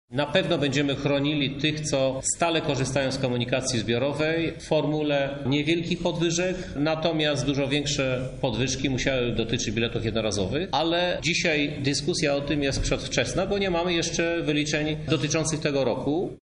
– tłumaczy Krzysztof Żuk, prezydent Lublina